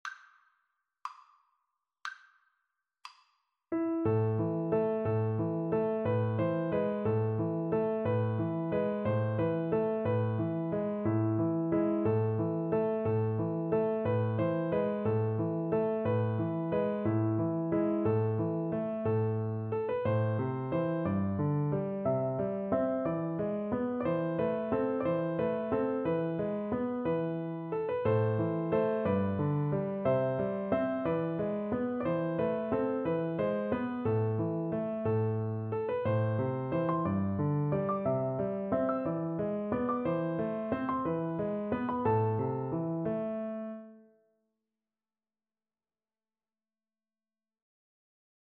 6/8 (View more 6/8 Music)
A minor (Sounding Pitch) (View more A minor Music for Voice )
~ = 90 Munter